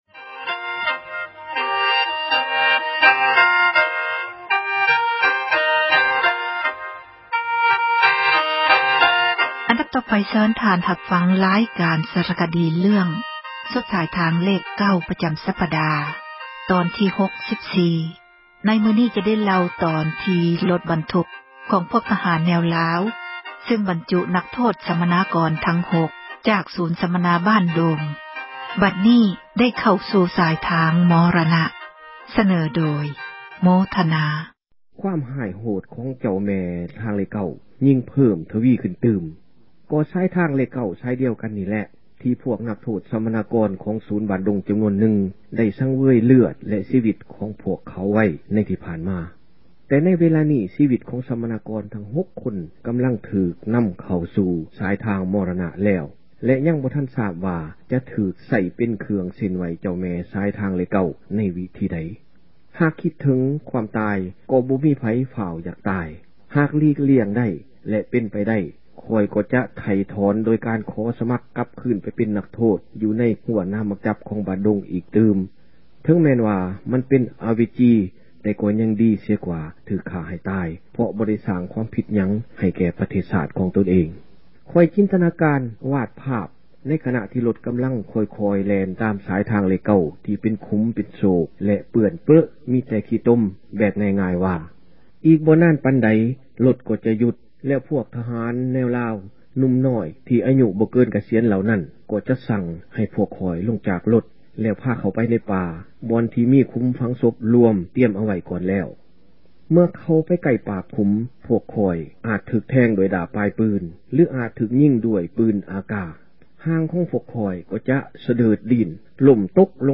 ອັນດັບຕໍ່ໄປ ເຊີນທ່ານ ຮັບຟັງ ຣາຍການ ສາຣະຄະດີ ເຣື້ອງ ”ສຸດສາຍທາງເລຂ 9” ປະຈໍາສັປດາ ຕອນທີ 64. ໃນມື້ນີ້ ຈະໄດ້ເລົ່າ ຕອນທີ່ ຣົຖບັນທຸກ ຂອງພວກທະຫານ ແນວລາວ ຊຶ່ງນໍານັກໂທດ ສັມມະນາກອນ ທັງຫົກ ຈາກສູນ ສັມມະນາ ”ບ້ານດົງ” ບັດນີ້ໄດ້ ເຂົ້າຊູ່ສາຍທາງ ມໍຣະນະ.